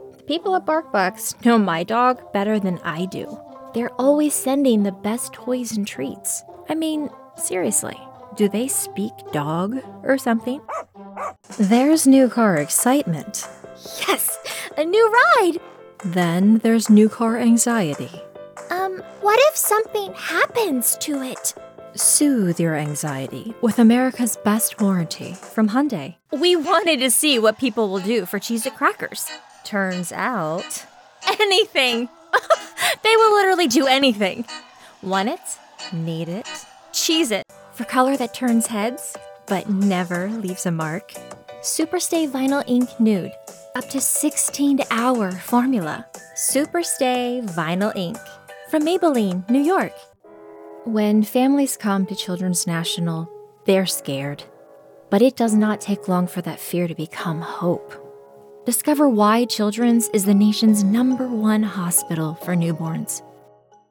Professional Voice Over, Animation, Commercial
youthful, articulate, conversational sounding voice over talent
Commercial-2025.mp3